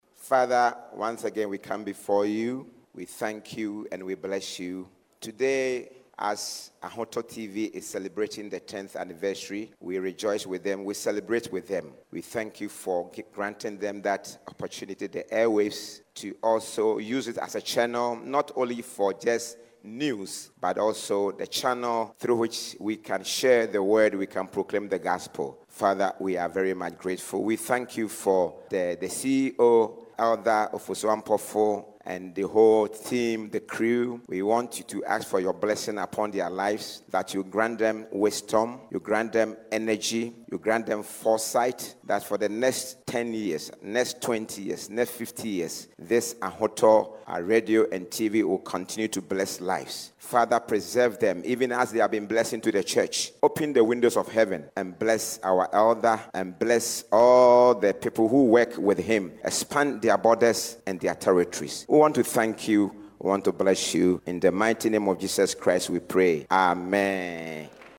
SAKUMONO, Ghana, 28 December: Ahotor 92.3 FM, a subsidiary of Universal Multimedia, has successfully climaxed its 10th anniversary celebrations with a special thanksgiving service held at the Pentecost International Worship Centre (PIWC), Sakumono Branch.